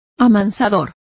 Complete with pronunciation of the translation of tamers.